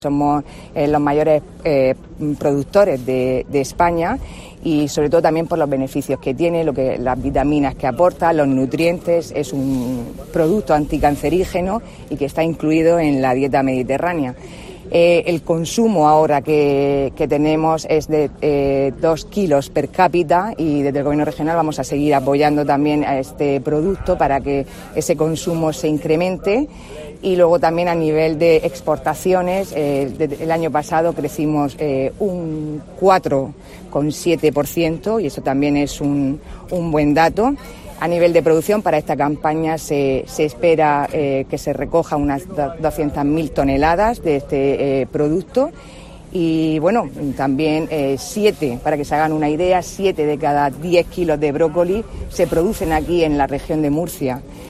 Sara Rubira, consejera de Agricultura
Declaraciones que ha realizado la consejera durante el acto del primer corte de la temporada, organizado por la asociación +Brócoli que desde 2010 trabaja por fomentar el consumo de esta verdura en nuestro país.